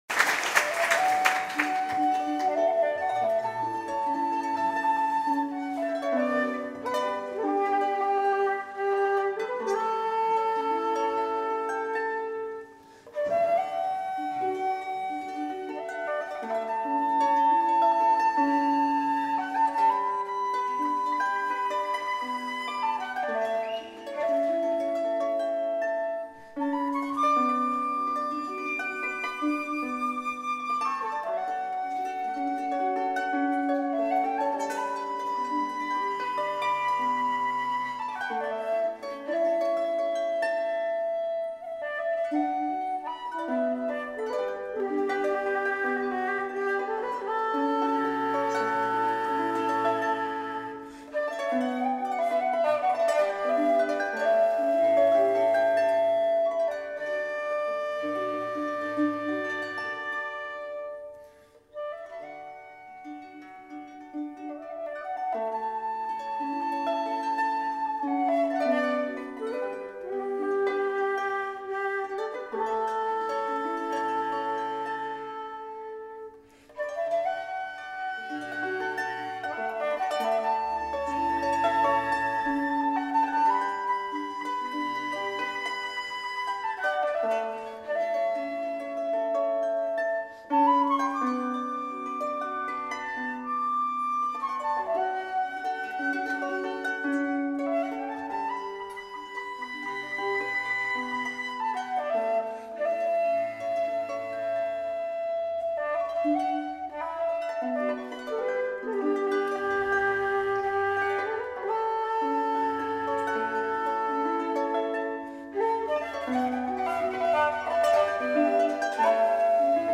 昨年、開催されたアイリッシュコンサートの、録音の一部を試聴出来ます。（mp3形式）